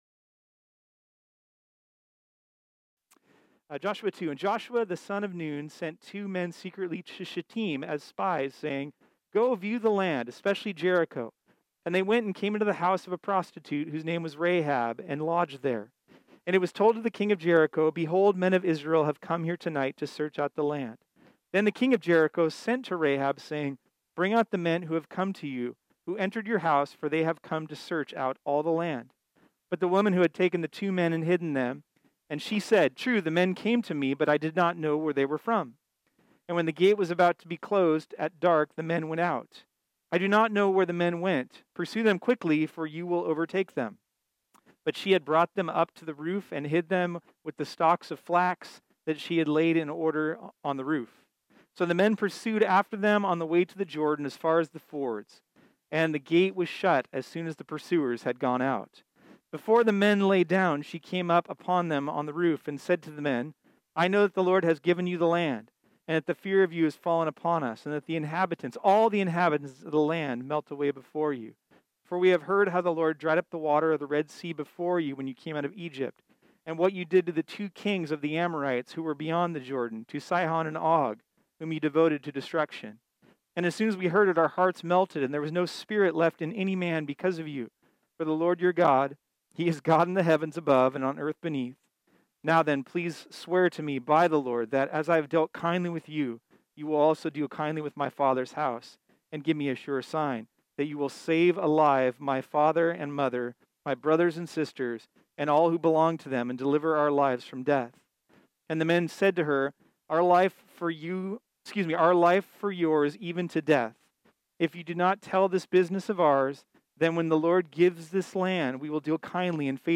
This sermon was originally preached on Sunday, September 20, 2020.